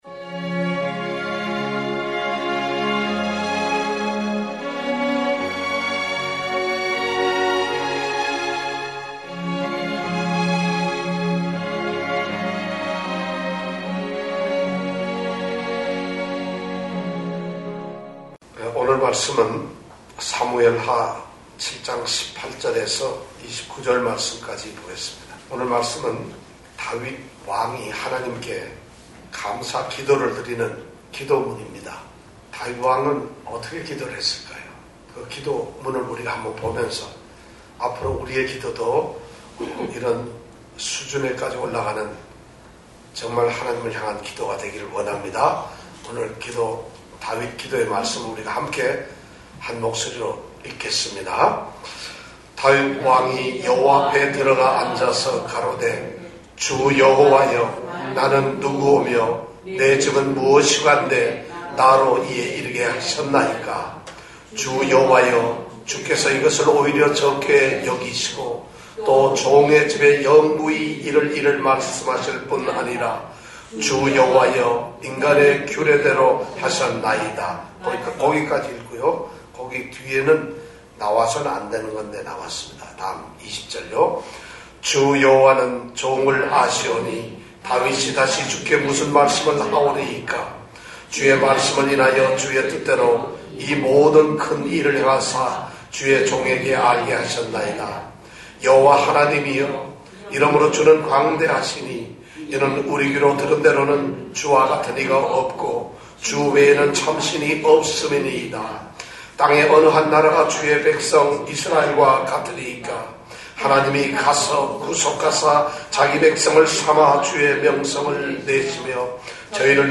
수요예배 설교